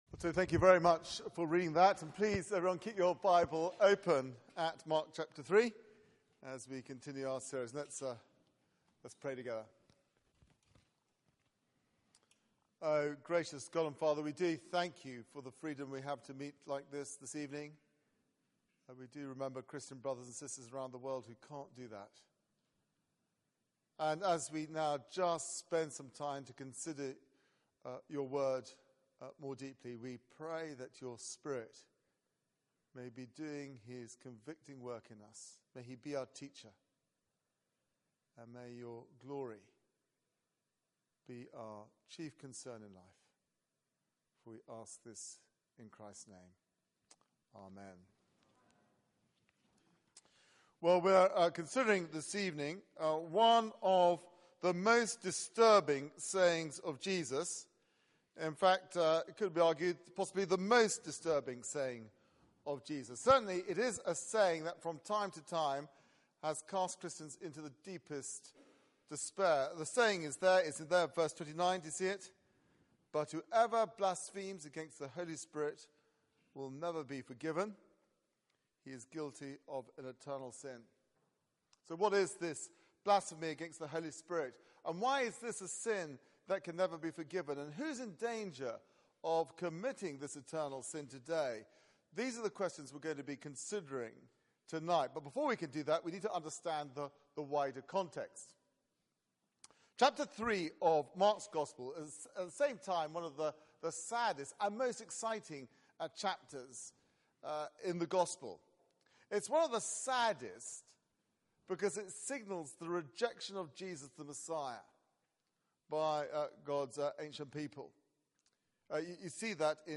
Bible Text: Mark 3:20-35 | Preacher